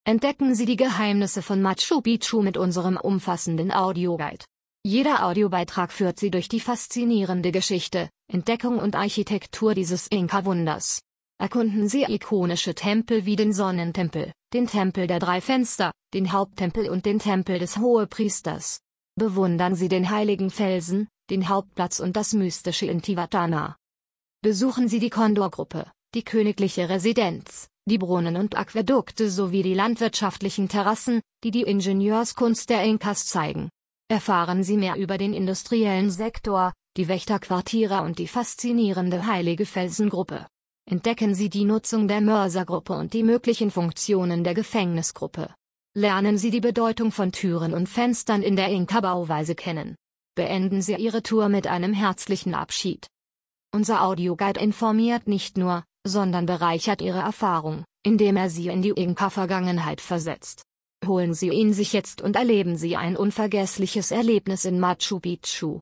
Audioguide von Machu Picchu auf Deutsch
Der Audioguide von Machu Picchu bietet ein immersives Erlebnis mit 26 sorgfältig erzählten Aufnahmen. Von der Begrüßung bis zum Abschied führt Sie jede Aufnahme durch die wichtigsten Orte der alten Inka-Stadt, wie den Sonnentempel, das Wächterhaus, den Intihuatana und vieles mehr.